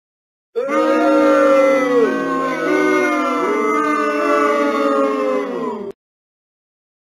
Boo-Sound-Effect.mp3